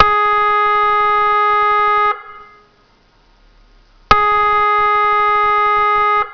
Sirena electrónica de gran potencia autónoma vía radio provista de señalización
Intermitente
Intermitente.wav